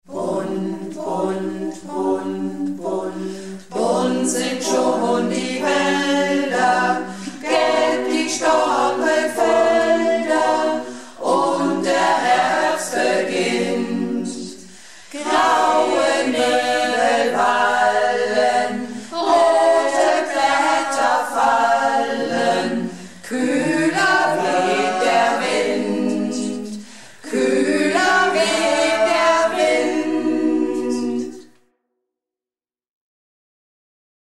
Malle Diven - Probe am 29.09.16